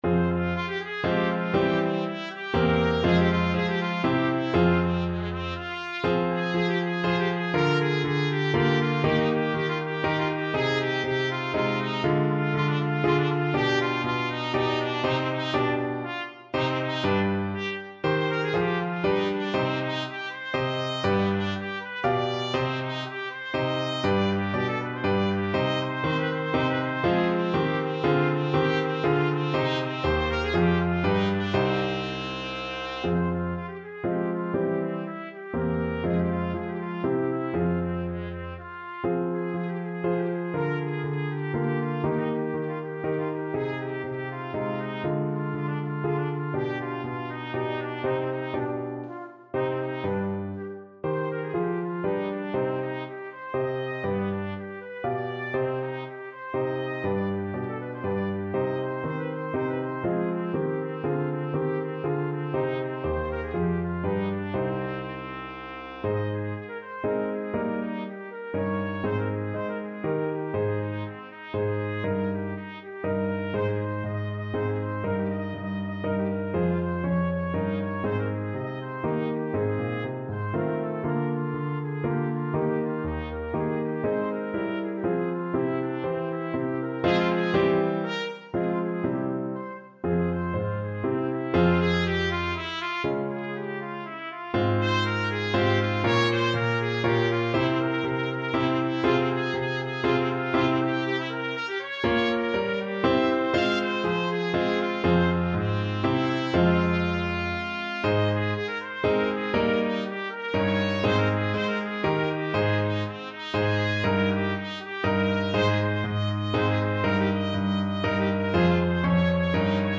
Trumpet
3/8 (View more 3/8 Music)
=120 Vivace (View more music marked Vivace)
Ab4-F6
F minor (Sounding Pitch) G minor (Trumpet in Bb) (View more F minor Music for Trumpet )
Classical (View more Classical Trumpet Music)